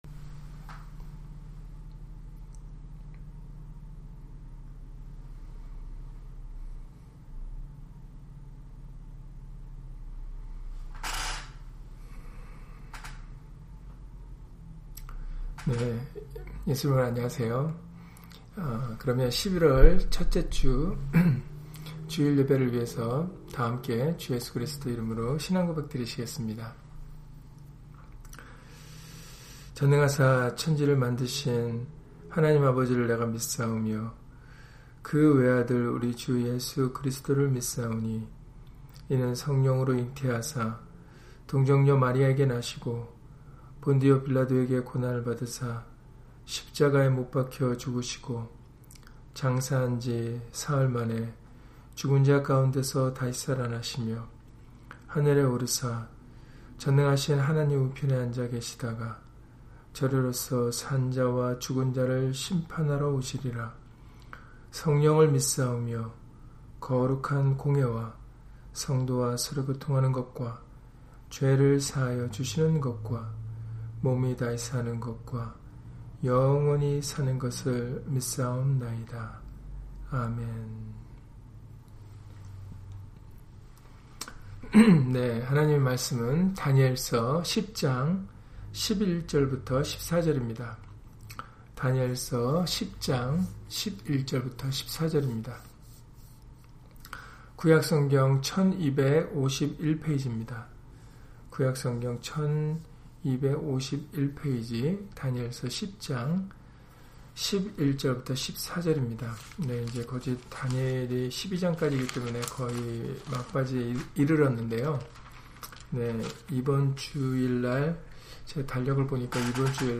다니엘 10장 11-14절 [내가 네게 보내심을 받았느니라] - 주일/수요예배 설교 - 주 예수 그리스도 이름 예배당